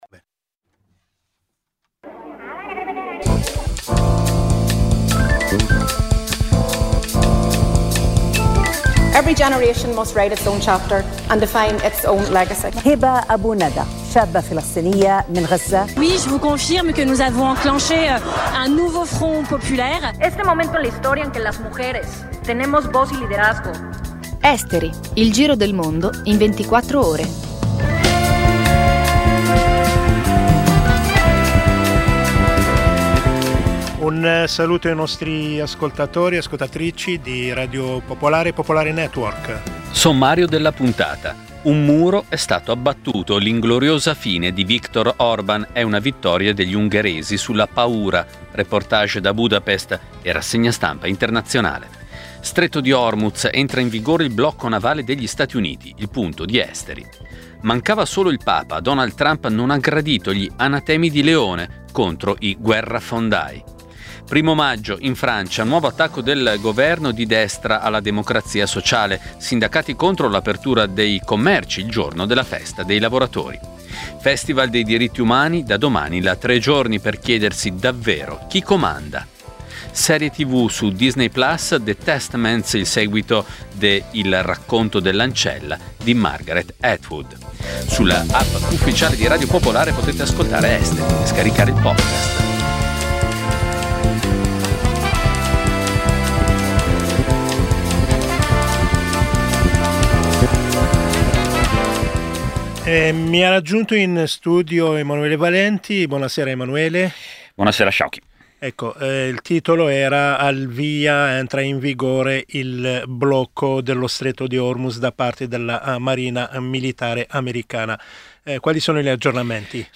Il programma combina notizie e stacchi musicali, offrendo una panoramica variegata e coinvolgente degli eventi globali.